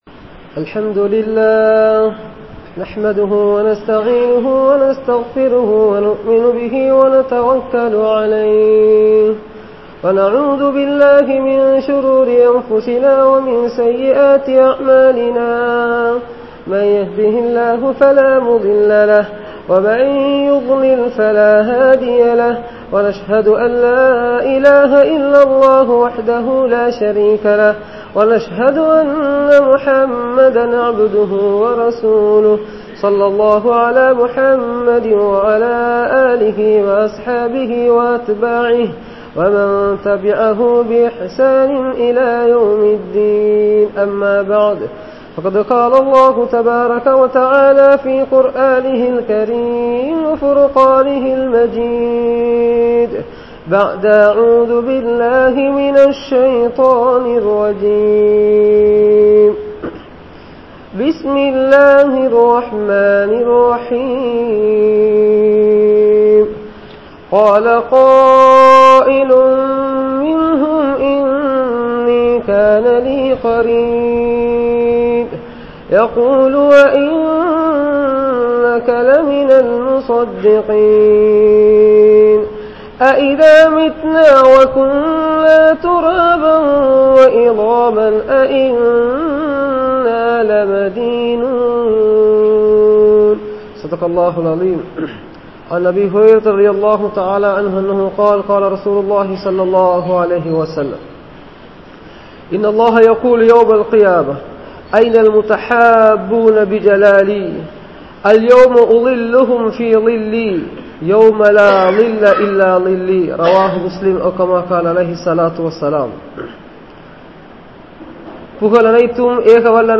Vaalkaiyai Theermanikkum Nanparhal (வாழ்க்கையை தீர்மானிக்கும் நண்பர்கள்) | Audio Bayans | All Ceylon Muslim Youth Community | Addalaichenai
Gorakana Jumuah Masjith